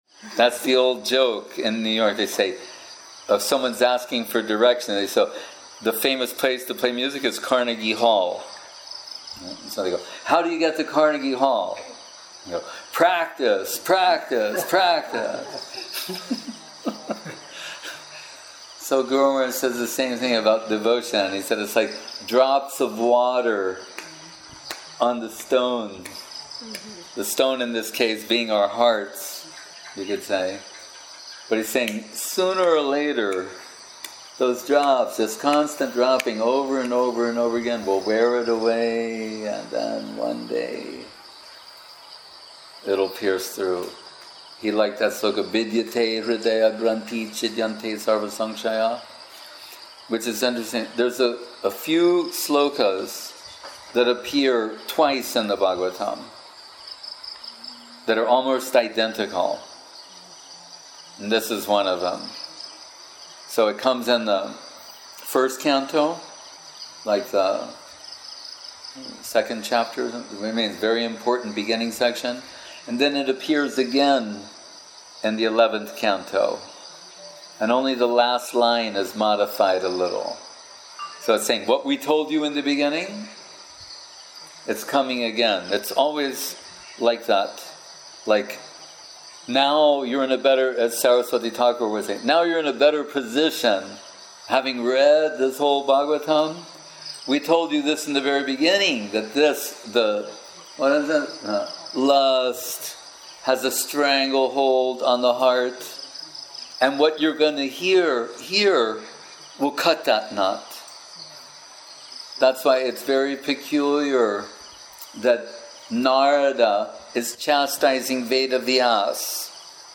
Unofficial evening talk.